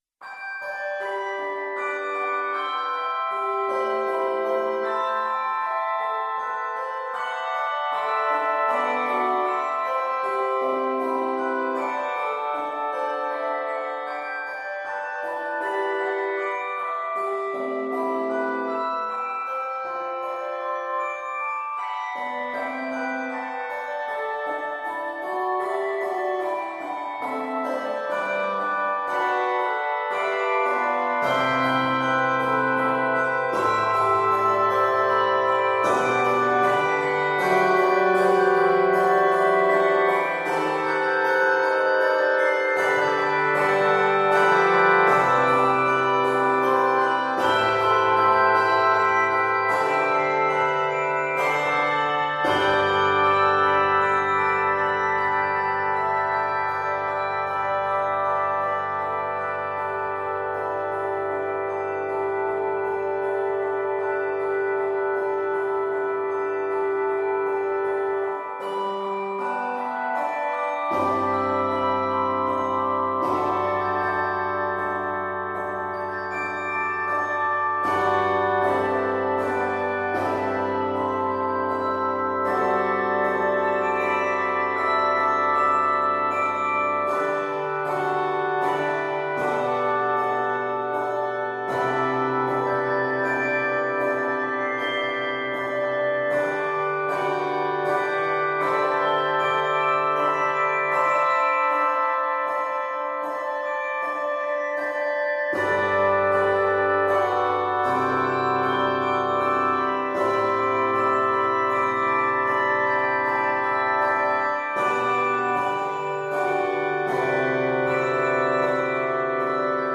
Scored in G Major, this piece is 54 measures.